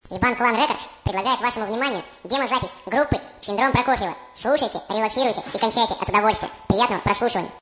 Демо